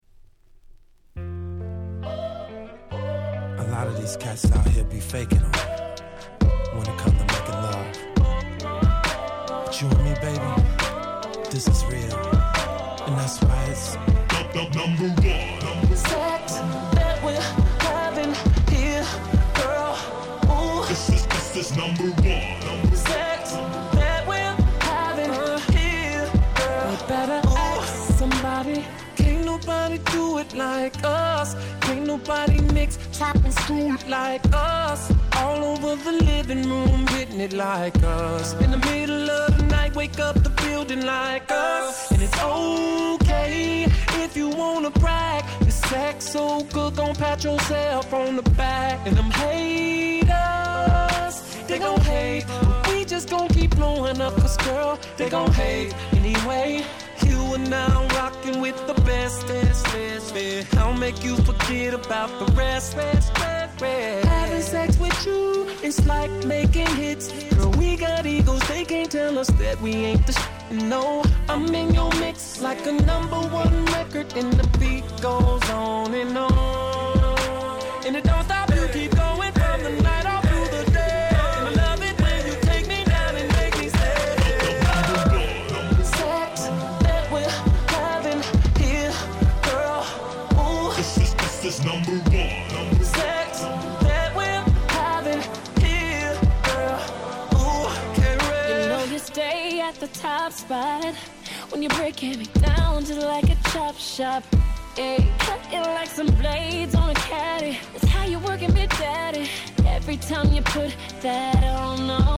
09' Smash Hit R&B !!